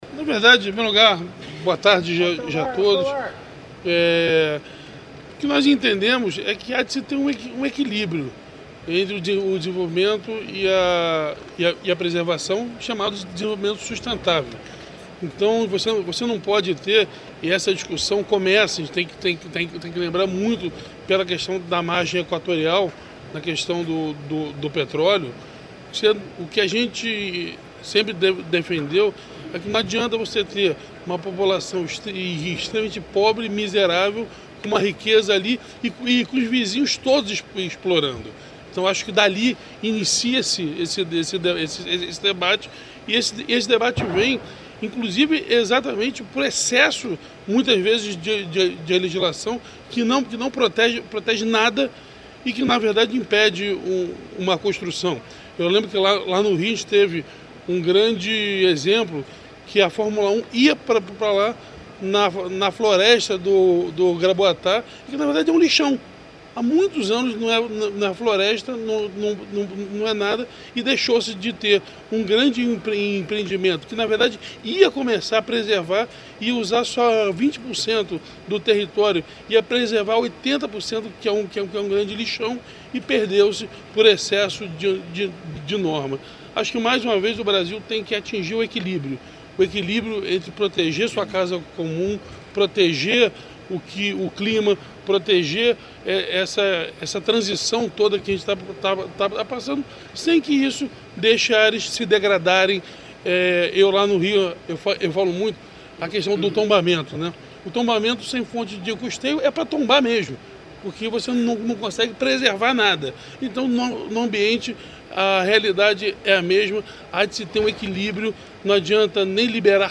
Sonora do governador do Rio de Janeiro, Claudio Castro, sobre a 13ª edição do Cosud